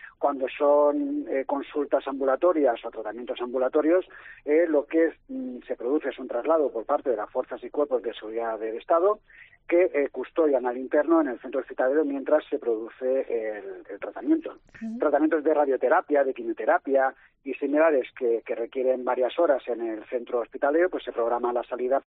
Un funcionario de prisiones, en COPE Andalucía: "Miles de presos siguen tratamientos en la cárcel"